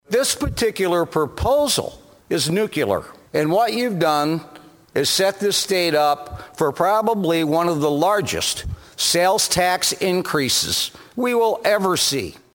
SENATOR TONY BISIGNANO OF DES MOINES SAYS REQUIRING TWO-THIRDS VOTES IN THE LEGISLATURE TO RAISE INCOME TAXES WILL TIE THE HANDS OF FUTURE LAWMAKERS WHO HAVE TO RESPOND TO DECLINING TAX REVENUE DURING AN ECONOMIC DOWNTURN.